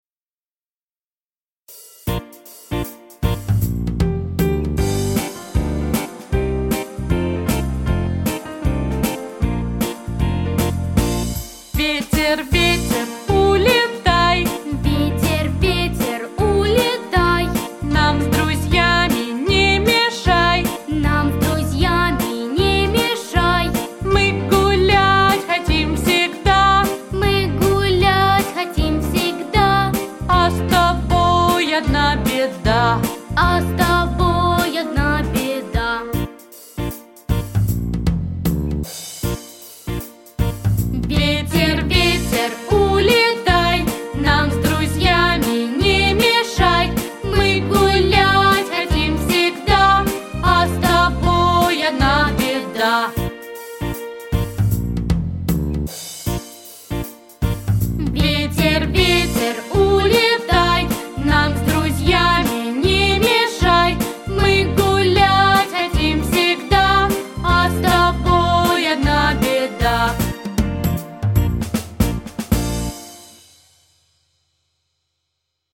• Категория: Детские песни
распевки